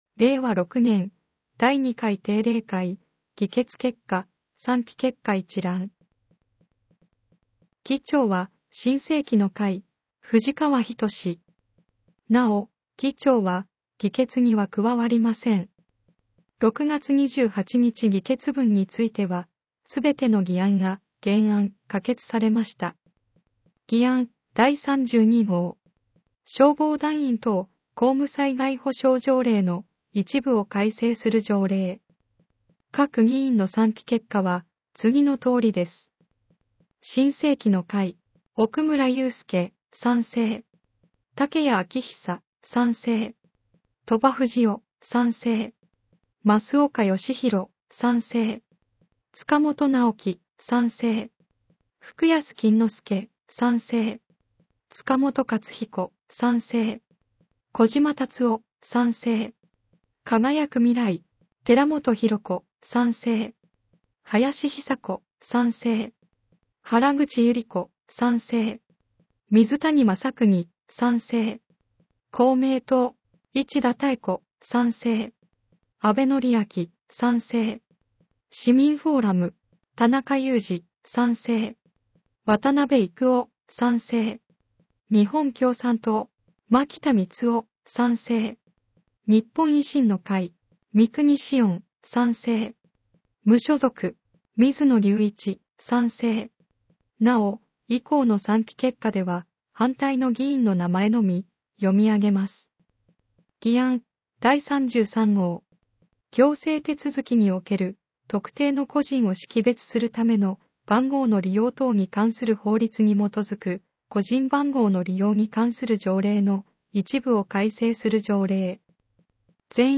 『声の議会広報』は、「みよし議会だより きずな」を音声情報にしたもので、平成29年6月15日発行の第110号からボランティア団体「やまびのこ会」の協力によりサービス提供をはじめました。